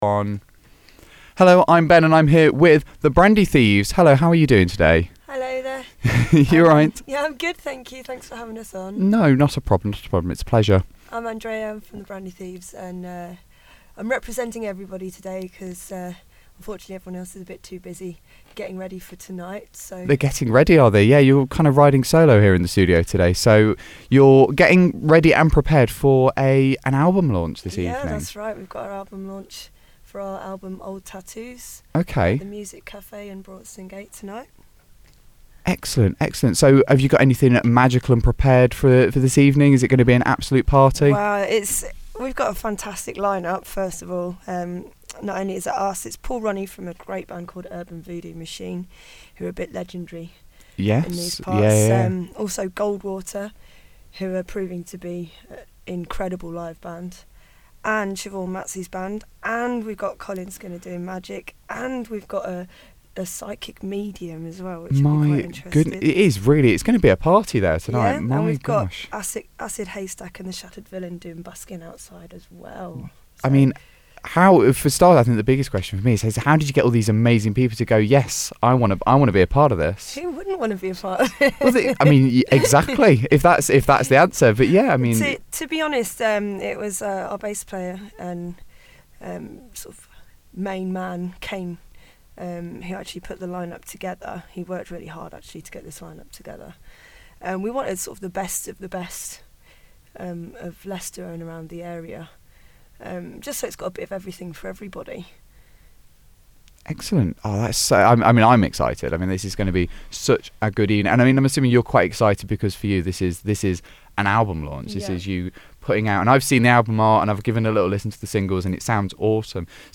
The Brandy Thieves – Interview and Album Launch | Demon Online
Main-Interview.mp3